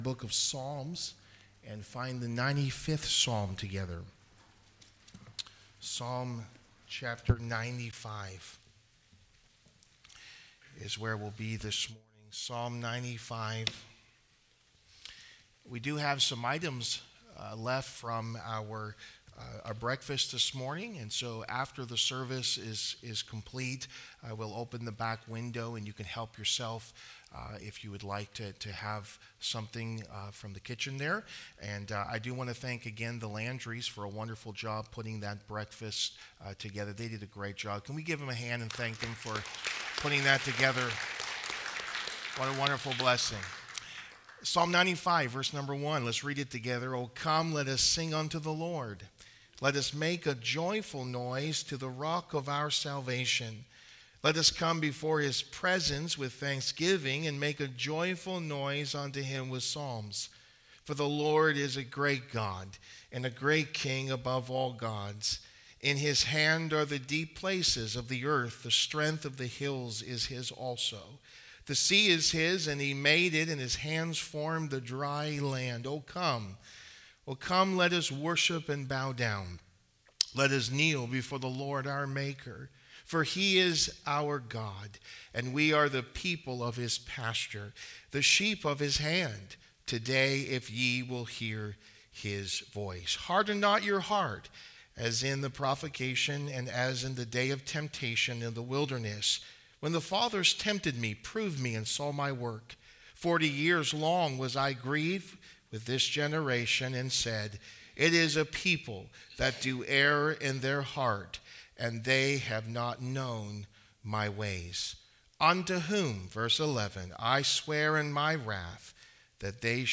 The Priority of Gratitude | Sermons